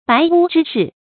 白屋之士 注音： ㄅㄞˊ ㄨ ㄓㄧ ㄕㄧˋ 讀音讀法： 意思解釋： 指貧寒的士人。